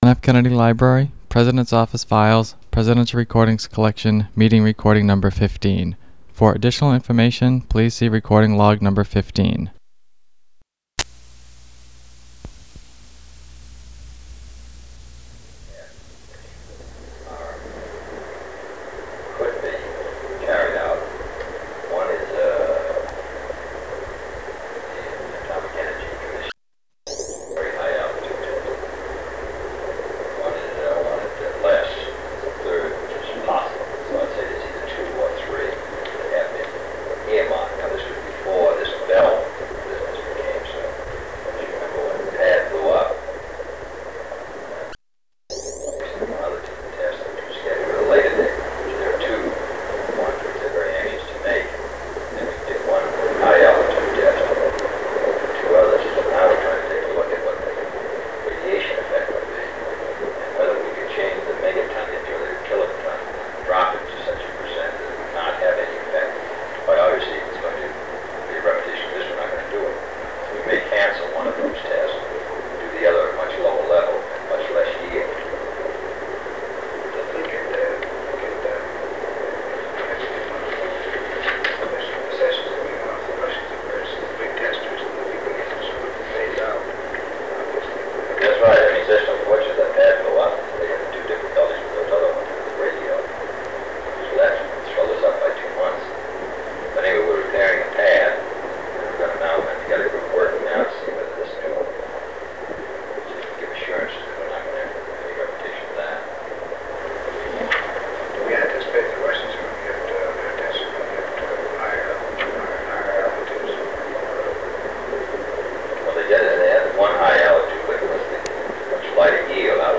Secret White House Tapes | John F. Kennedy Presidency Meeting on U.N. Strategy Rewind 10 seconds Play/Pause Fast-forward 10 seconds 0:00 Download audio Previous Meetings: Tape 121/A57.